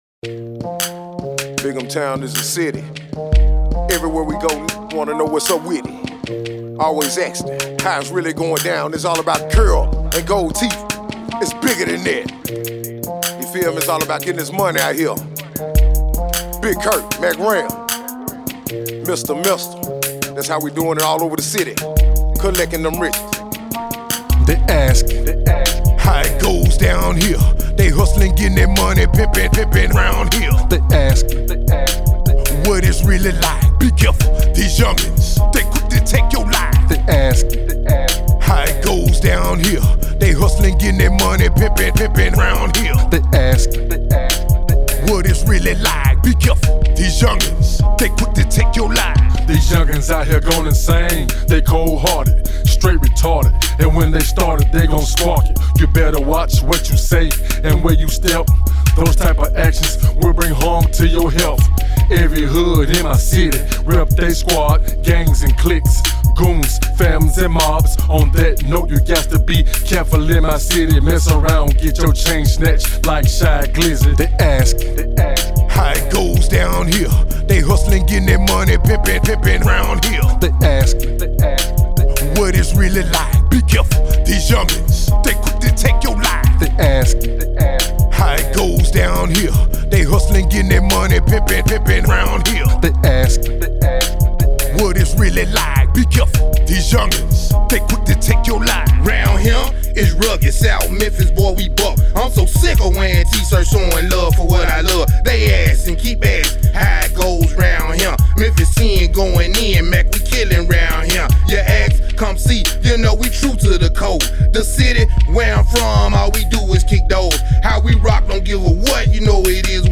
Hiphop
Southern rap